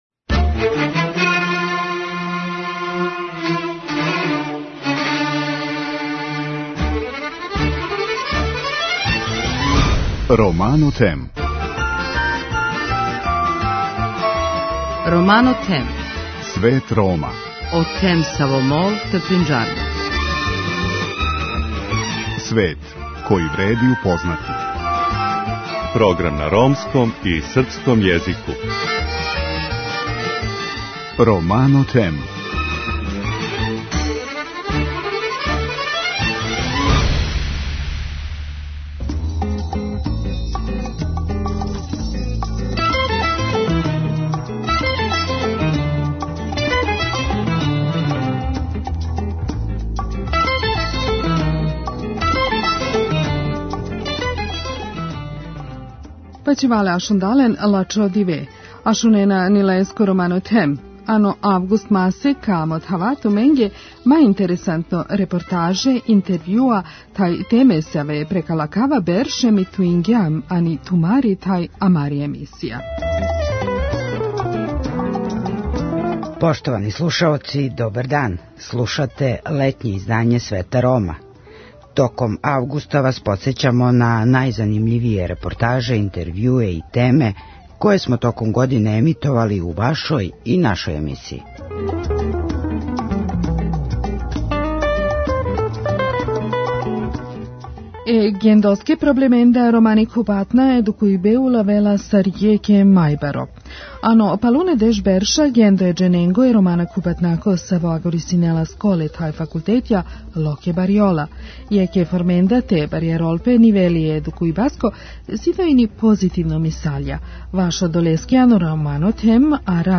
У данашњем издању емисије угостићемо два госта.